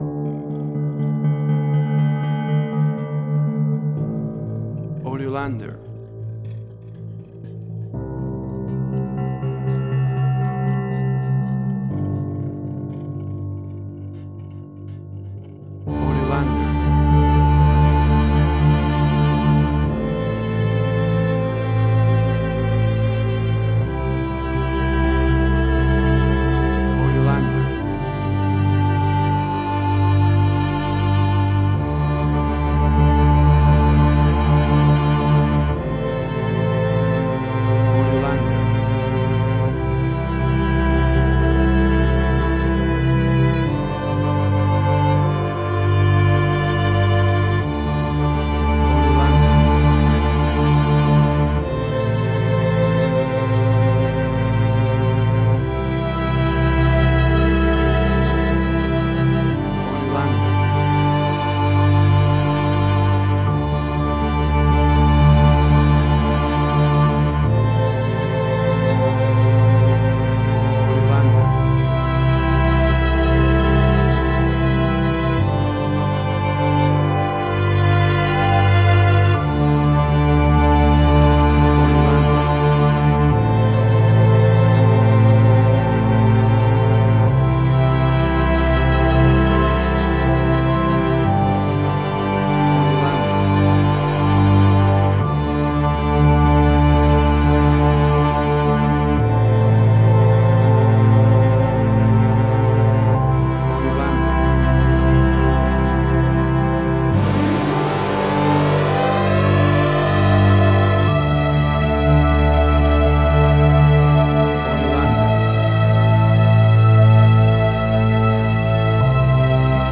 Post-Electronic.
Tempo (BPM): 60